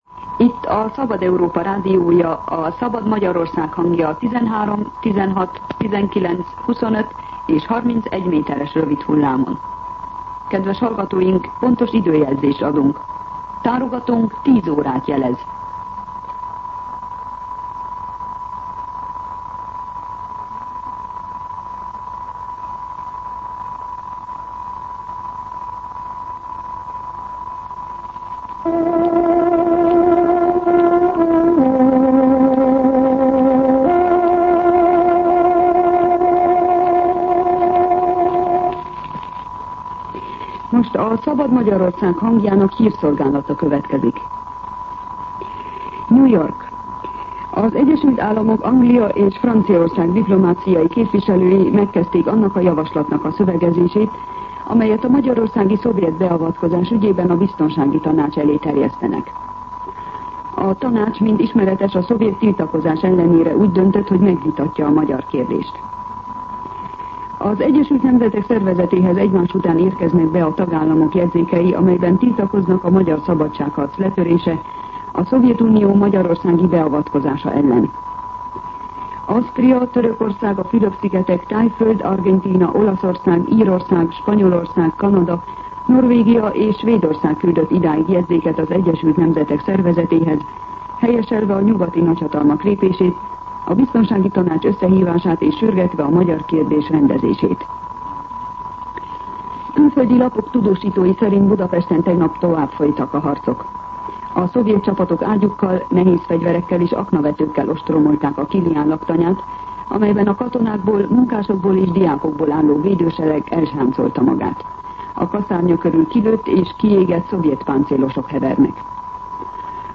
10:00 óra. Hírszolgálat